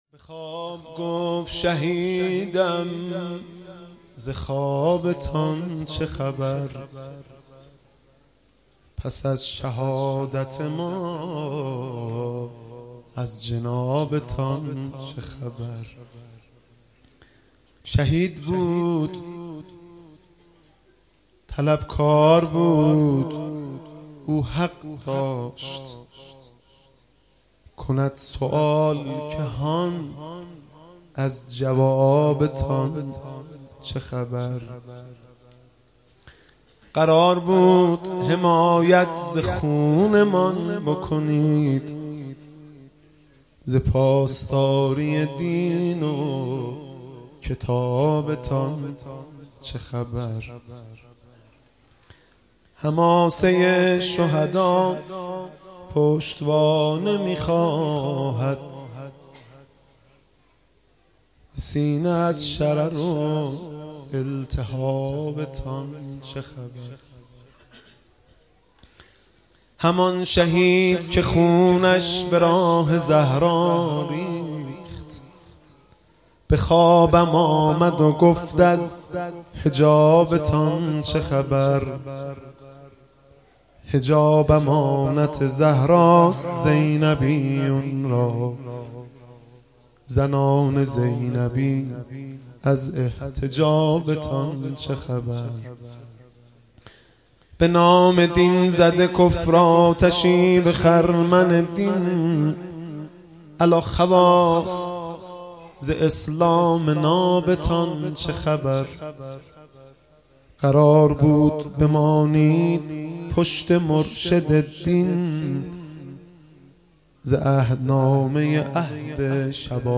هفتگی 21 تیر97 - شعر خوانی - به خواب گفت شهیدم ز خوابتان چه خبر